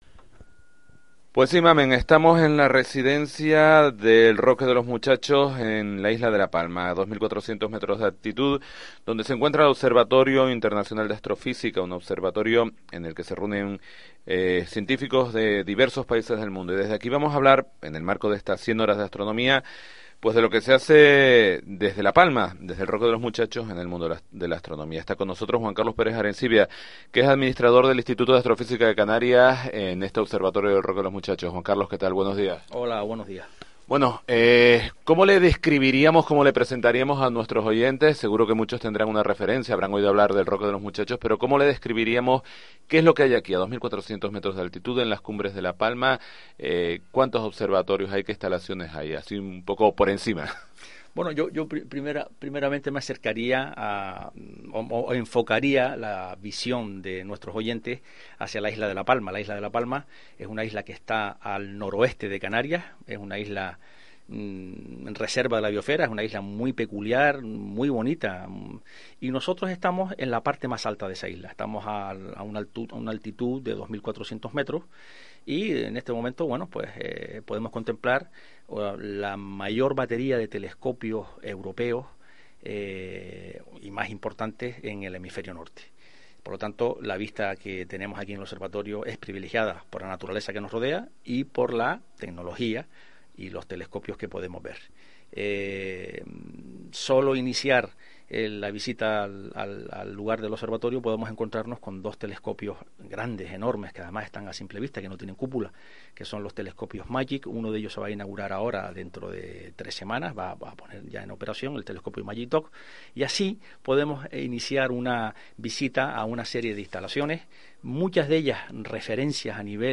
España Directo, interview